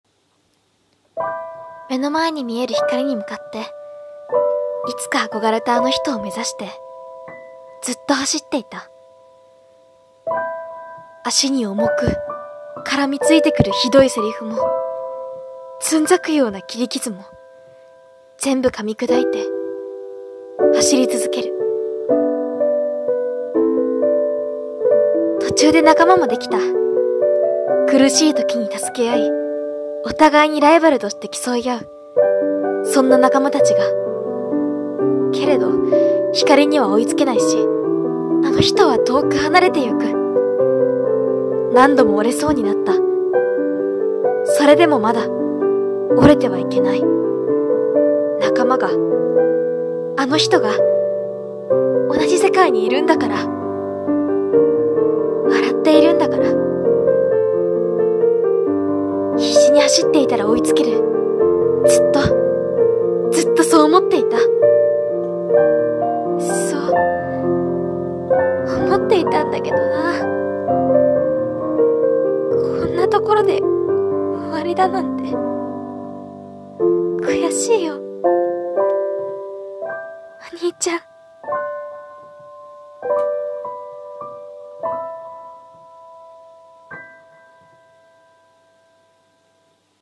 声劇 目標